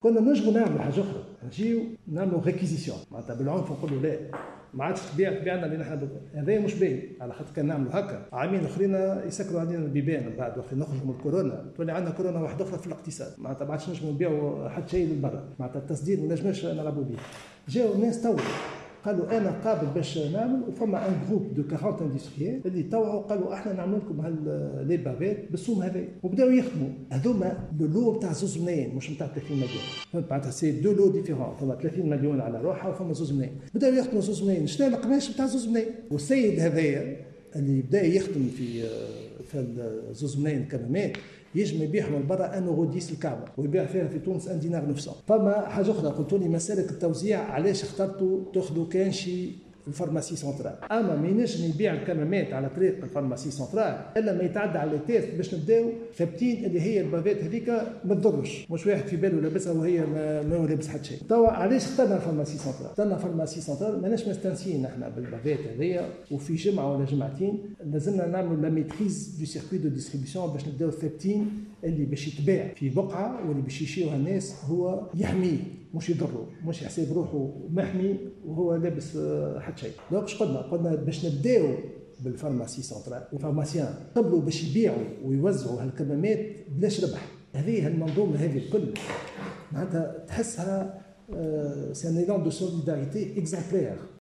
أكد وزير الصناعة صالح بن يوسف خلال إجابته على تساؤلات النواب في جلسة استماع عقدتها صباح اليوم الاربعاء لجنة الاصلاح الاداري والحوكمة ومقاومة الفساد بالمجلس أن صناعة الكمامات ليست حكرا على صناعيين دون غيرهم.